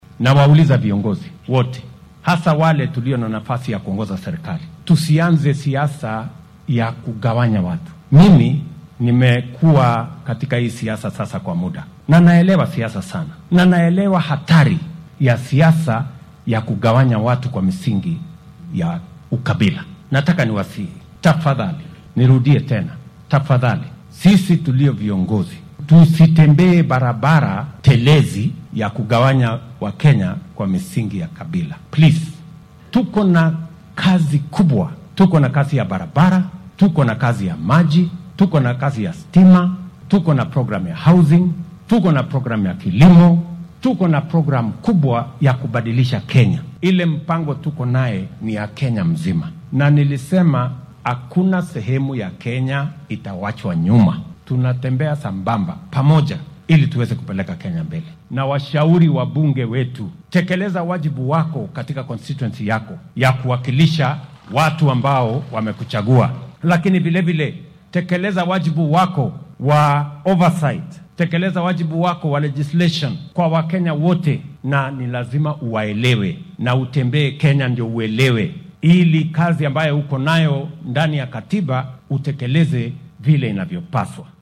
Arrintan ayuu sheegay xilli uu munaasabad kaniiseed uga qayb galay fagaaraha Amutala Stadium ee deegaan baarlamaneedka Kimilili ee ismaamulka Bungoma .